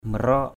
/mə-rɔ:ʔ/ (d.) dây củ rùa. haraik maraok h=rK m_r<K dây củ rùa.